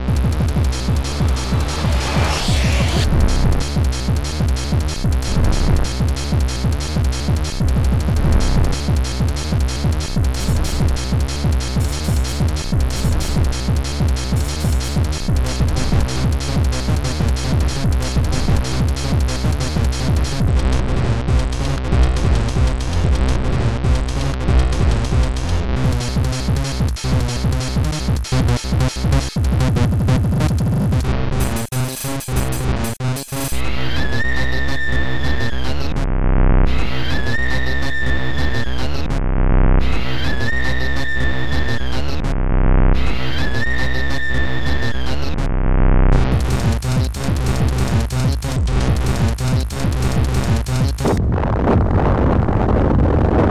Protracker M.K.